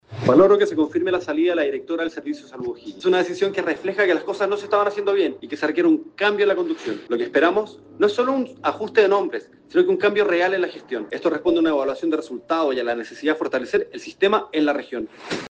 El diputado por el Distrito 15 Fernando Ugarte, valoró la salida de la recién removida directora, escuchemos:
DIPUTADOUGARTE.mp3